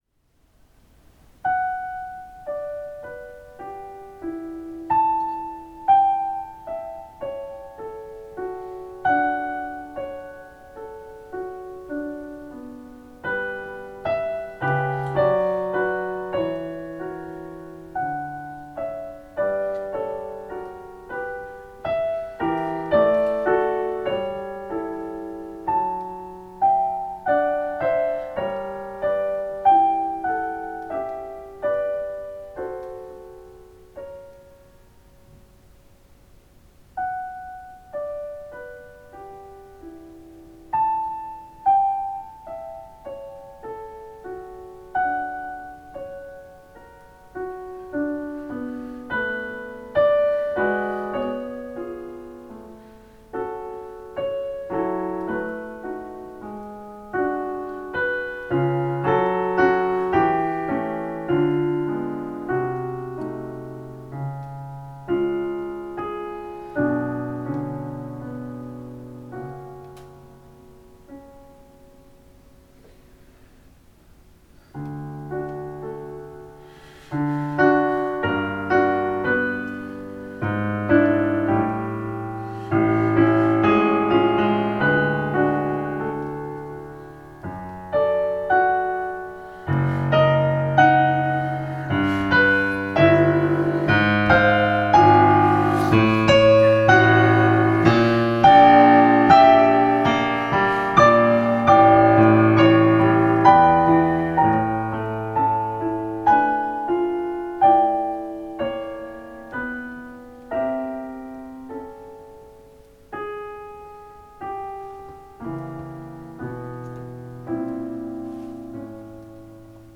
The Piano & Gear
• Kawai CA49 digital piano
• Zoom H1n audio recorder